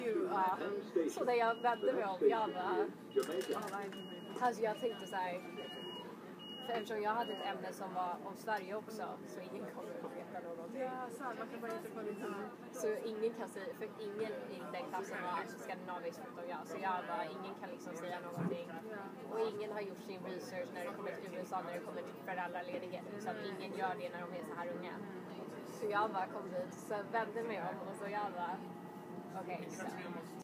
Field Recording #11
Location: 5/1/2014, 5:40 PM, Long Island Railroad Train Sounds Featured: The train announcement stating Jamaica station to be next, the doer closing beeps, the door closing, a hard shell suitcase being placed on the overhead compartment, passengers in my car talking; specifically the woman across from me to the woman next to me. I can’t identify the language they are speaking in, and occasionally they would mix in English in perfect American dialect.